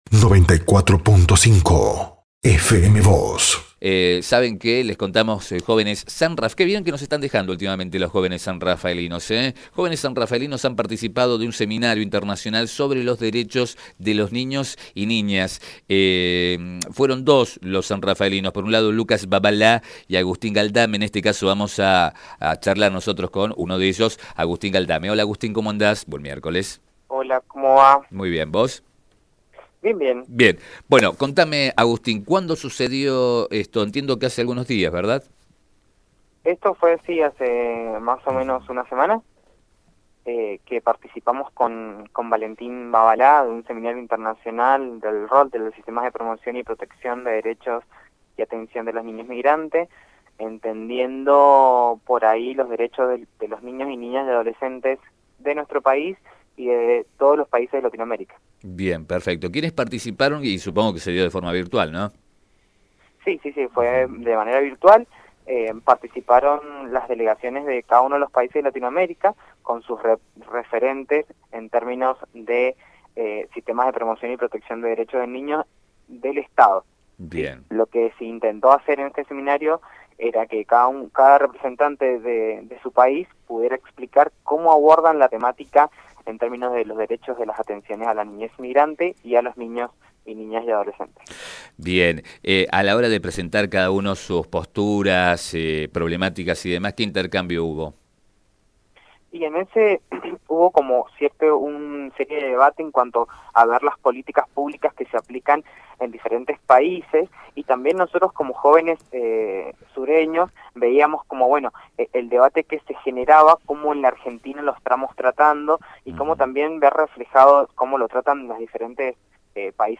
En diálogo con FM Vos (94.5) y Diario San Rafael